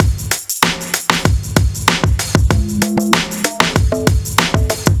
Track 14 - Drum Break 07.wav